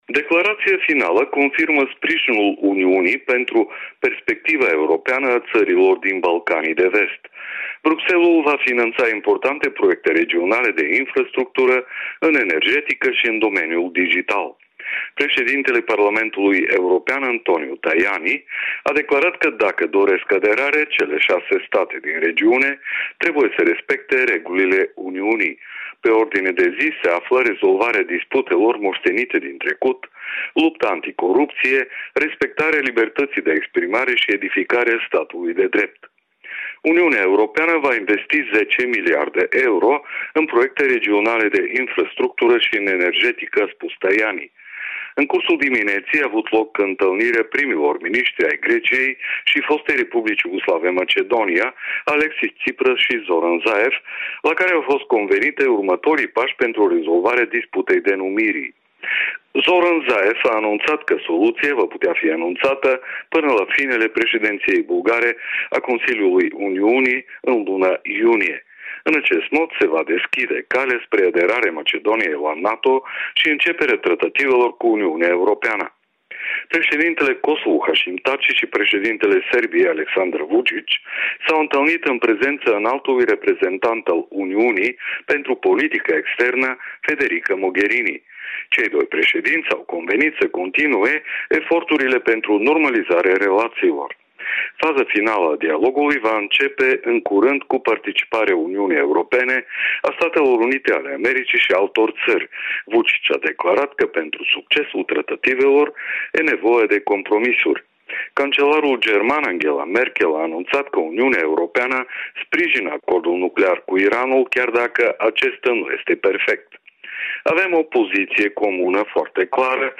Corespondența zilei de la Sofia